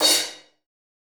CRASH 3.wav